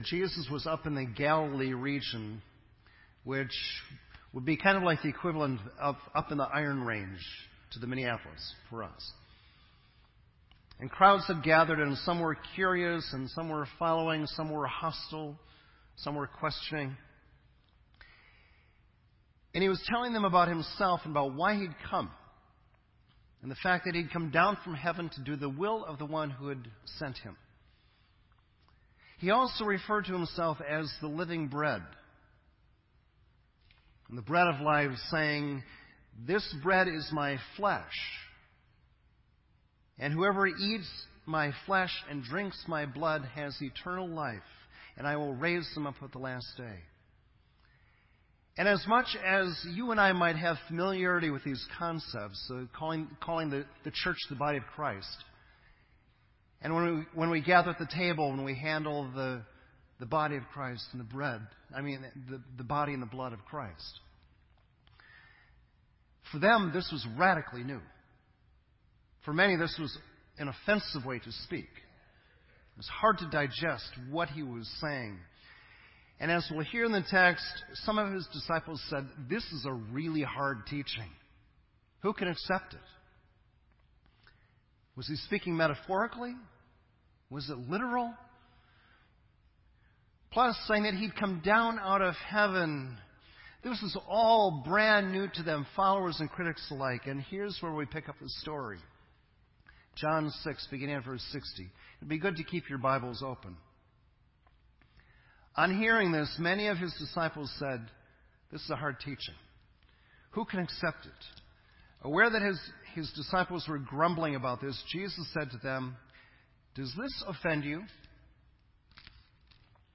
This entry was posted in Sermon Audio on January 18